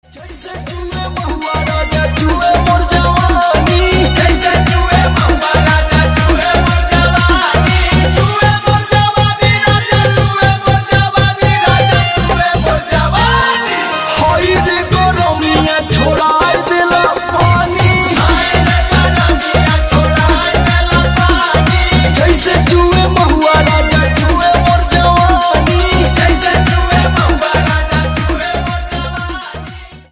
Bhojpuri Song
fusion of traditional folk beats and contemporary sounds